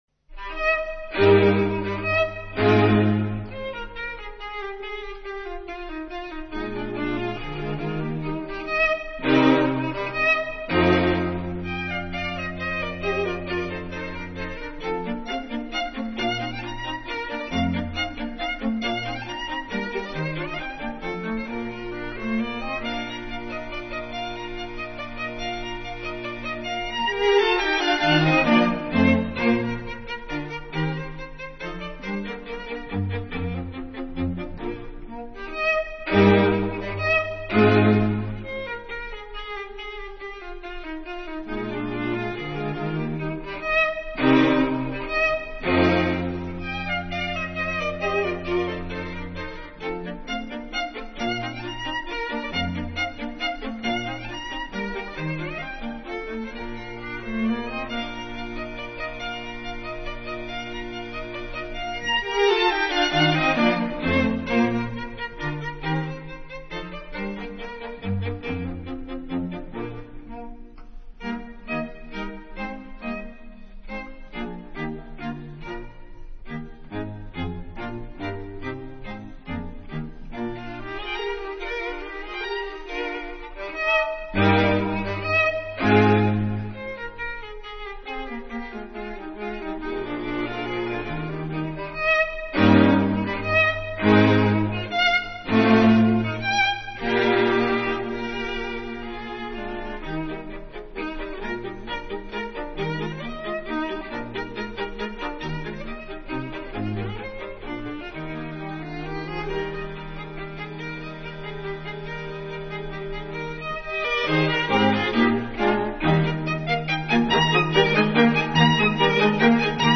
String Quartet in E flat major
Menuetto. Allegretto